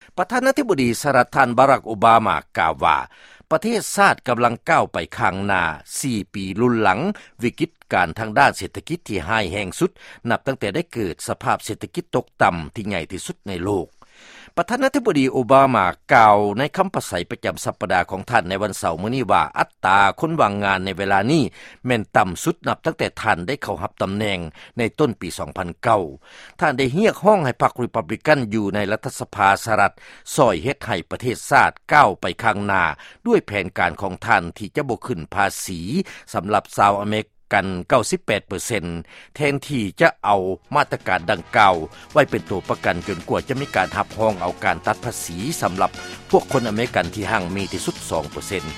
ຟັງຂ່າວ ທ່ານໂອບາມາ ກ່າວຄຳປາໄສ